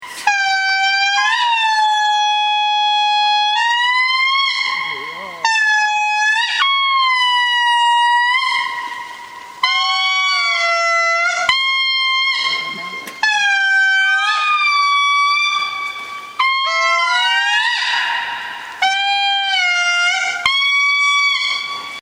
With the help of local guides we continued into the forest and directly to a quartet of Indri, the largest of the lemurs and the park's main attraction.